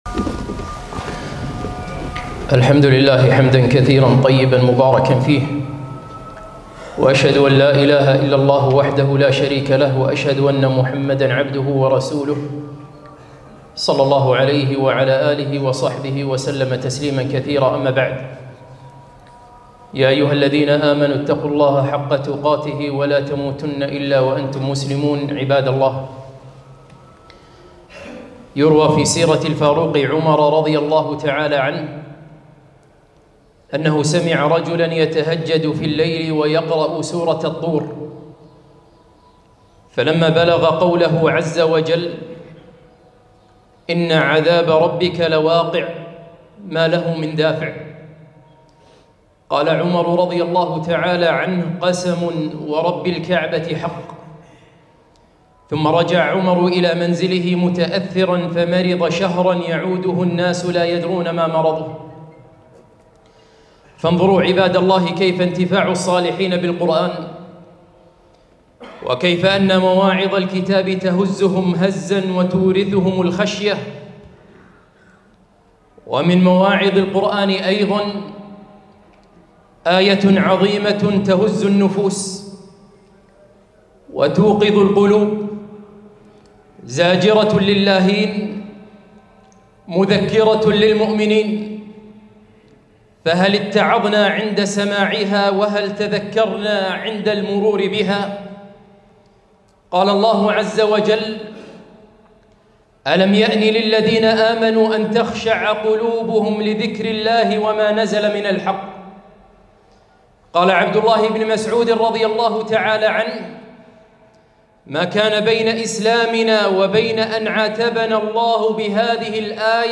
خطبة - قسوة القلوب ودخول شعبان